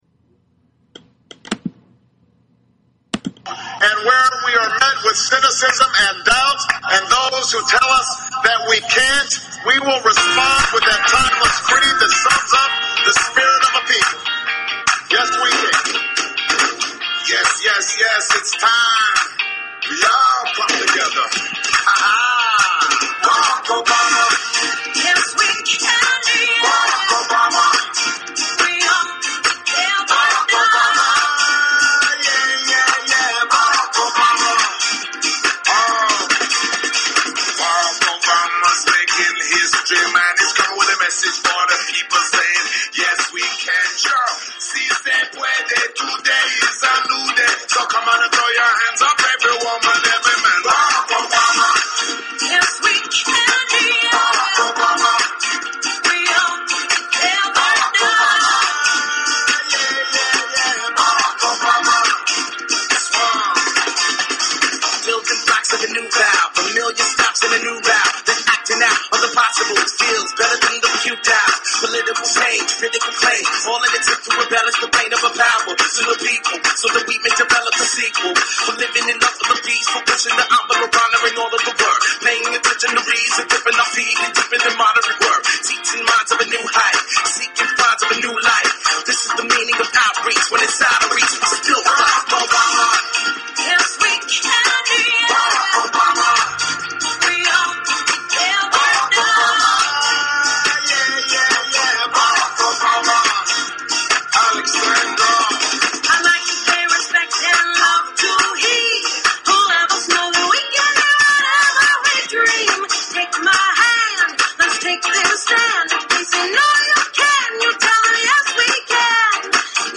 Talk Show Episode, Audio Podcast, Galactic_Roundtable and Courtesy of BBS Radio on , show guests , about , categorized as